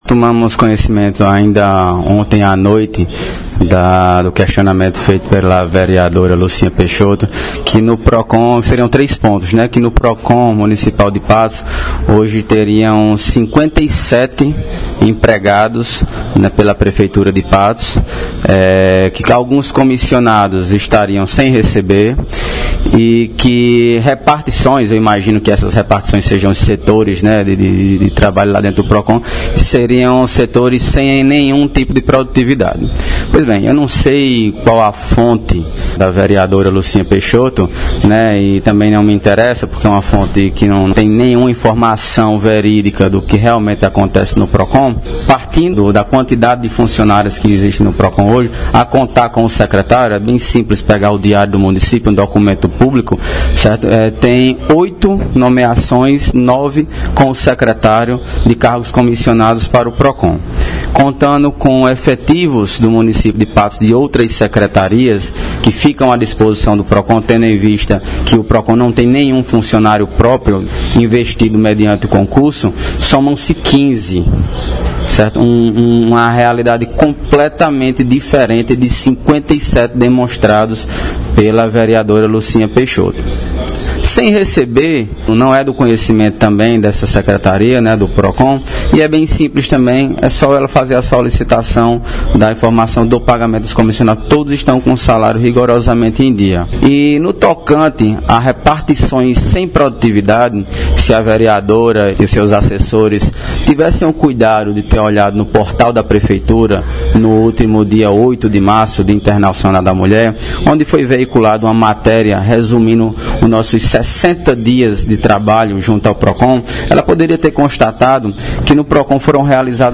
Fala do secretário do PROCON, Bruno Maia –